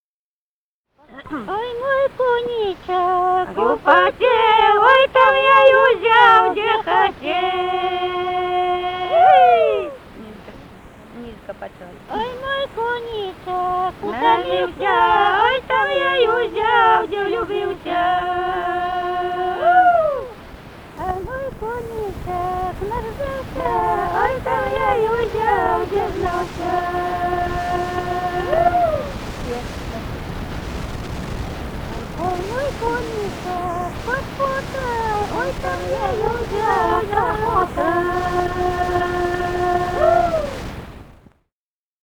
Музыкальный фольклор Климовского района 017. «Ой, мой коничек употел» (свадьбишная).
Записали участники экспедиции